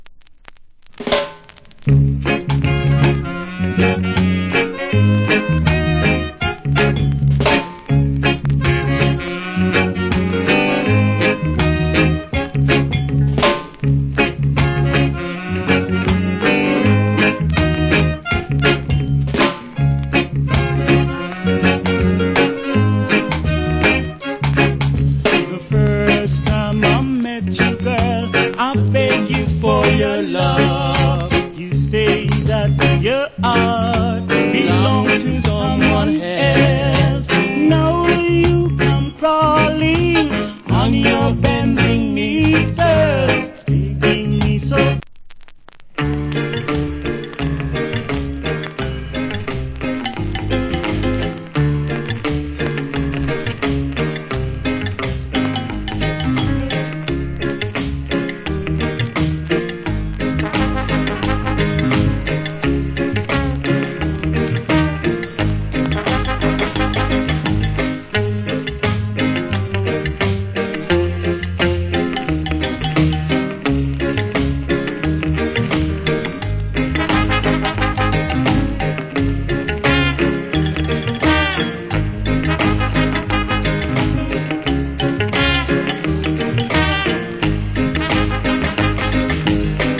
Wicked Rock Steady.